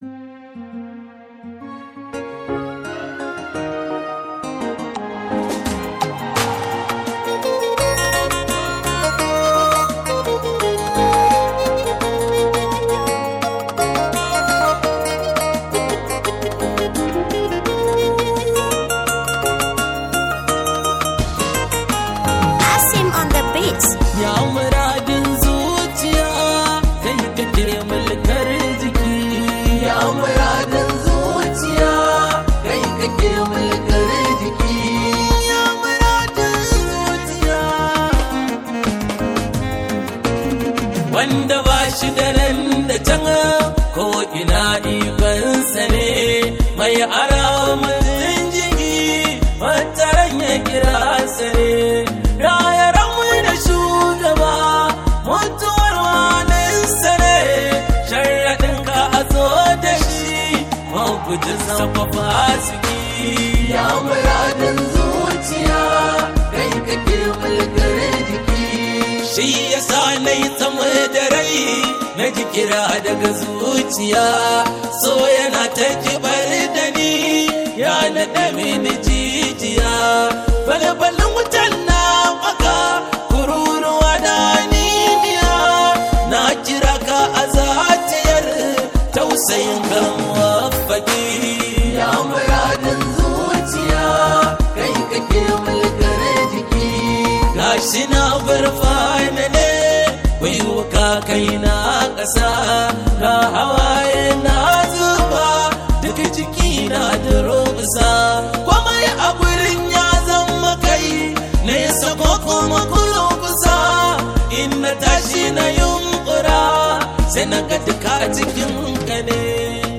Hausa Songs
wakar yabon Annabi Muhammad (S.A.W)